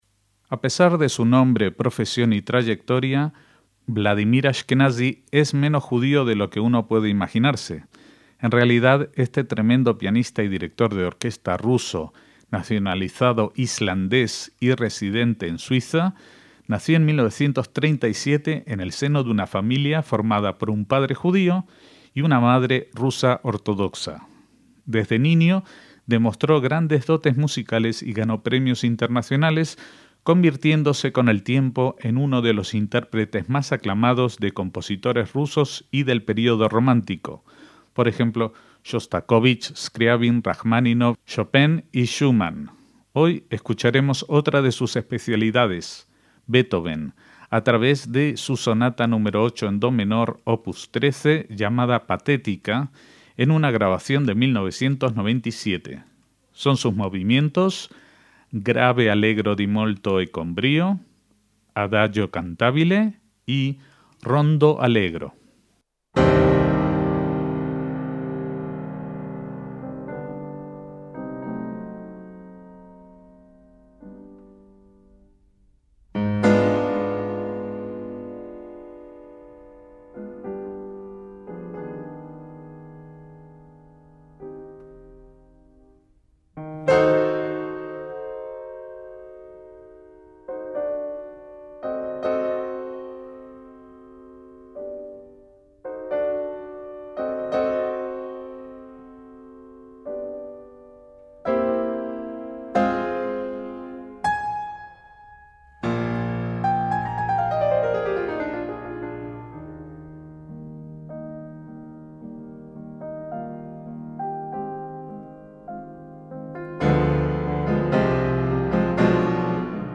el pianista ruso Vladimir Ashkenazi
en do menor
Grave; allegro di molto e con brio, Adagio cantabile y Rondo: allegro.